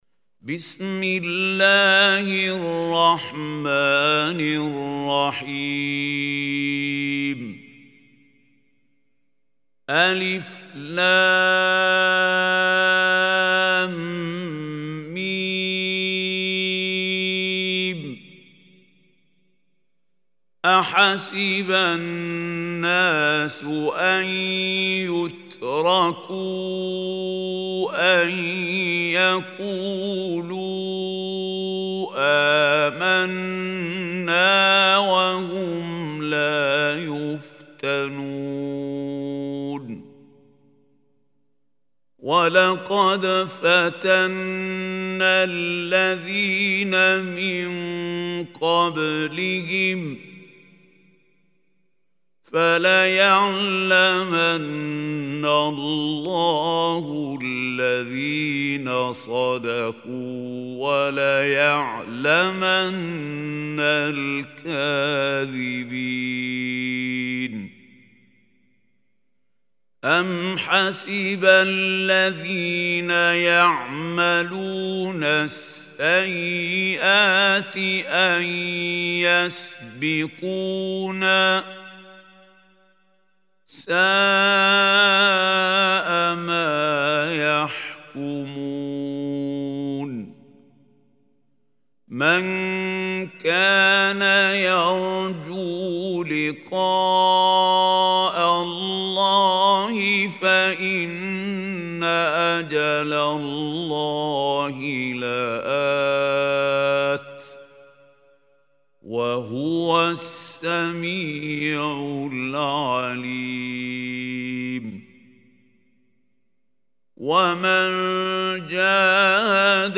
Surah Ankabut Online Recitation by Al Hussary
This beautiful recitation is in the voice of Mahmoud Khalil al Hussary.